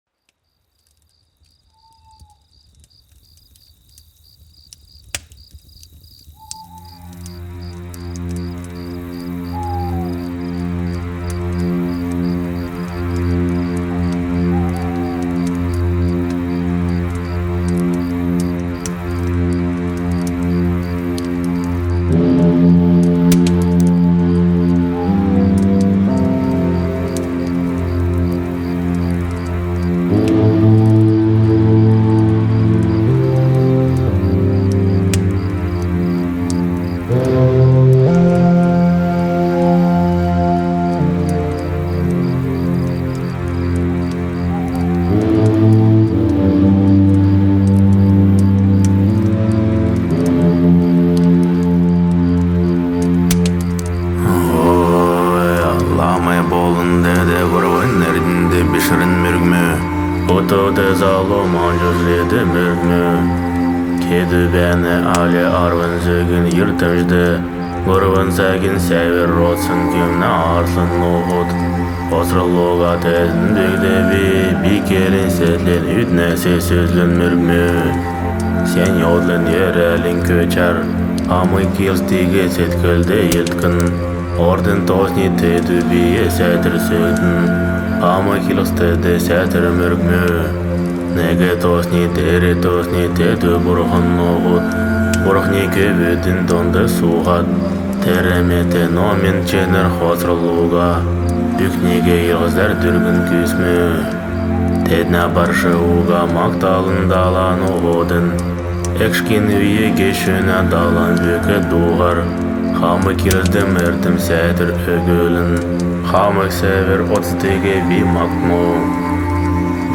гелюнг